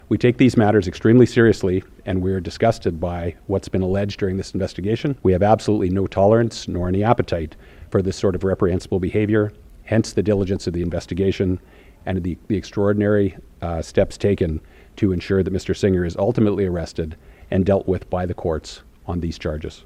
Calgary Police Service Chief Constable Mark Neufeld spoke to media yesterday surrounding the warrant. He mentioned the urgency and seriousness of the allegations.